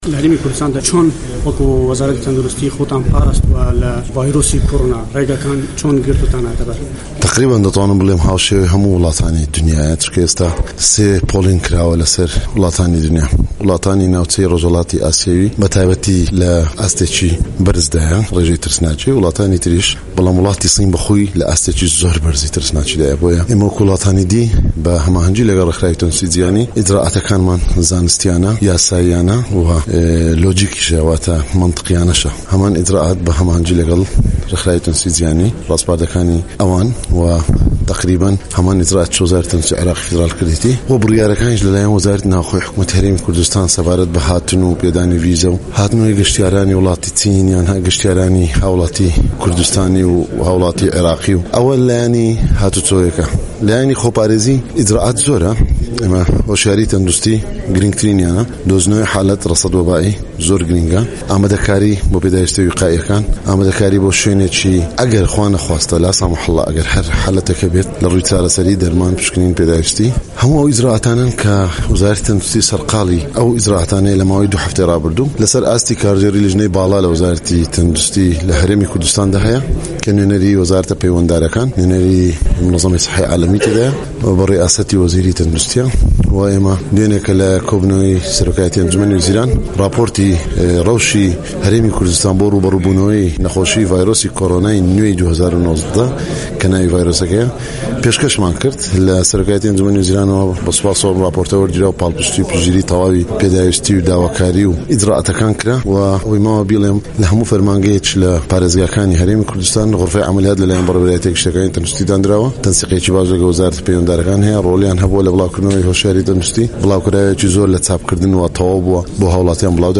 وتووێژ لەگەڵ وەزیری تەندروستی هەرێمی کوردستان